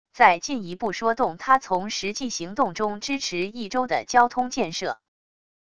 再进一步说动他从实际行动中支持益州的交通建设wav音频生成系统WAV Audio Player